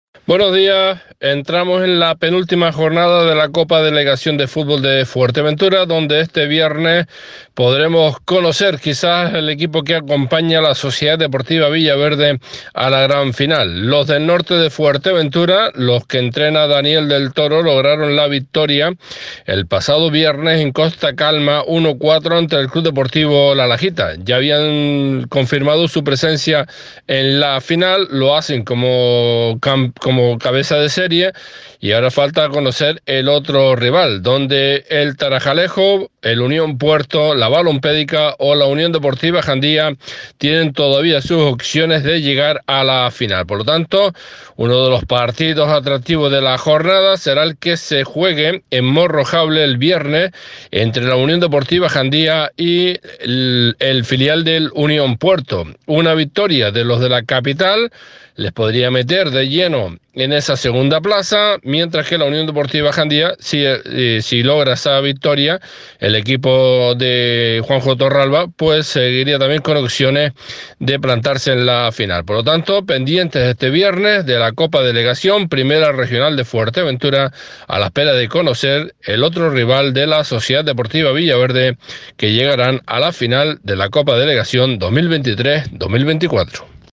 A Primera Hora, crónica deportiva